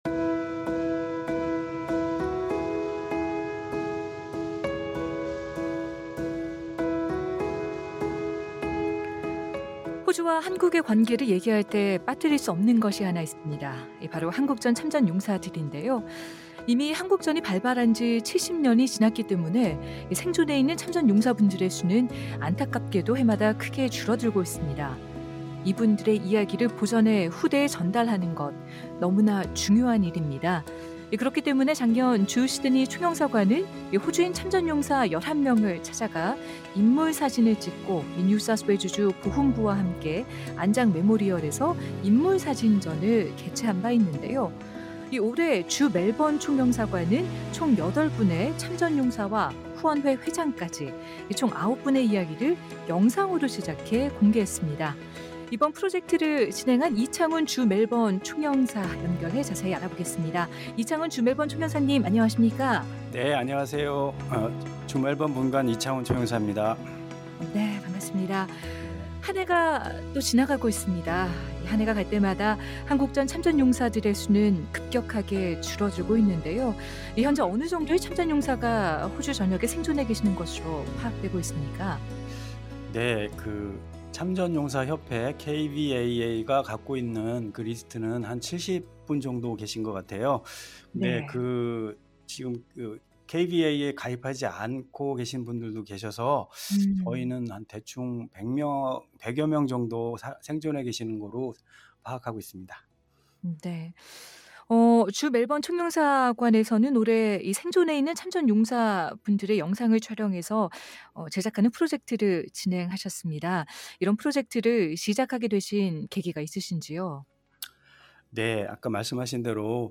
인터뷰: 이창훈 주멜번 분관 총영사, 90대 호주 한국전 참전 용사의 기억을 영상으로 남기다